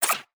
Tab Select 8.wav